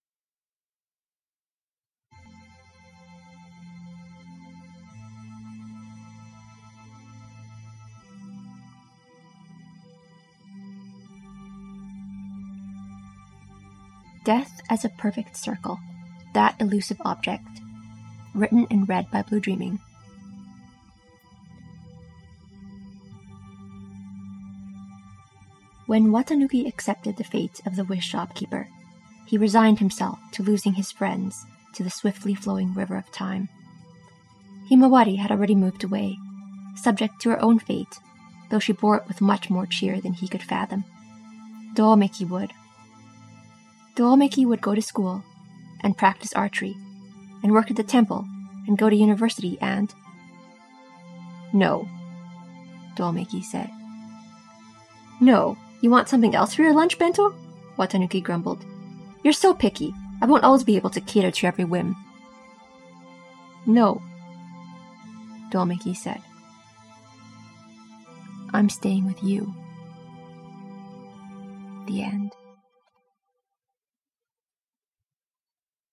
with music: